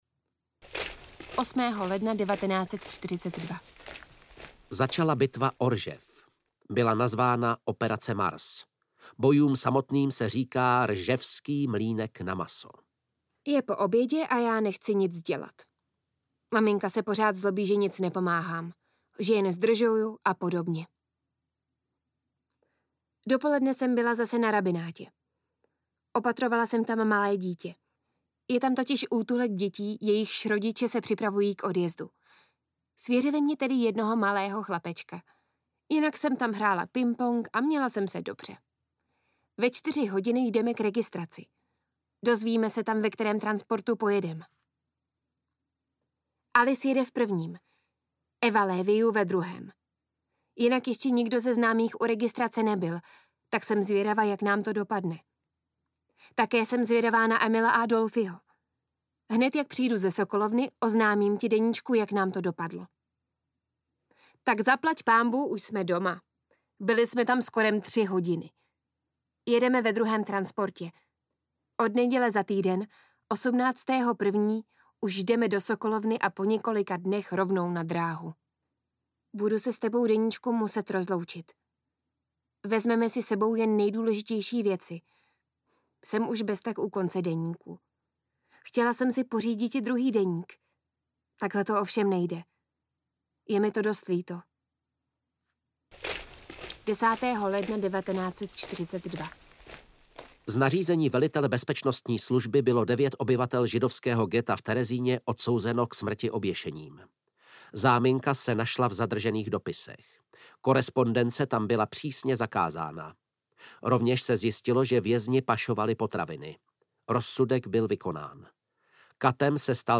Deník audiokniha
Ukázka z knihy